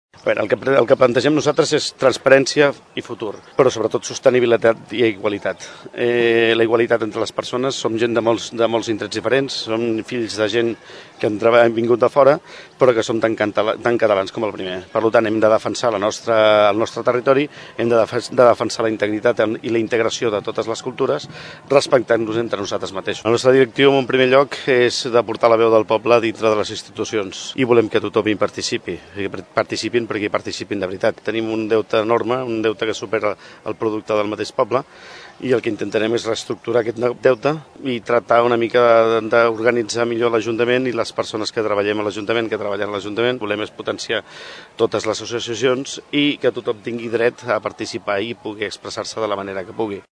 A la presentació, a la qual van assistir-hi més d’una trentena de persones, es van detallar algunes de les propostes en què treballarà el partit per potenciar la participació, controlar el deute municipal i aturar les desigualtats.